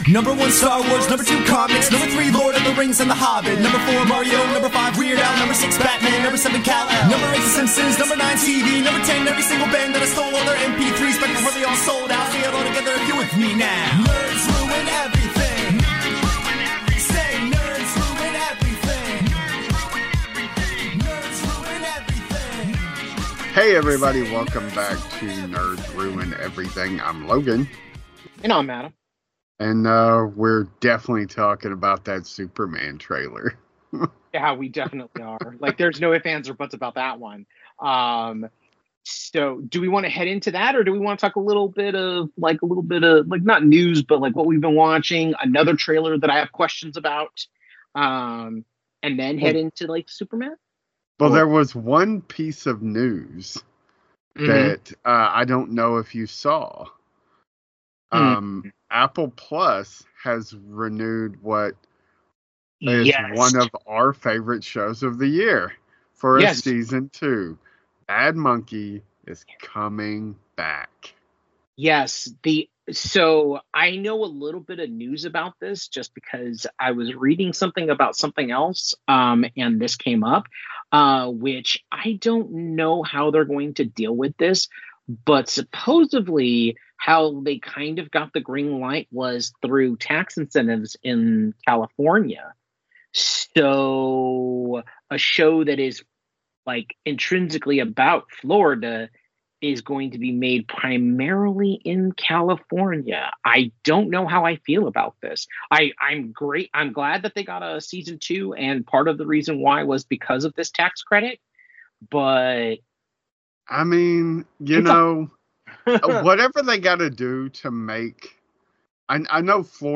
two nerds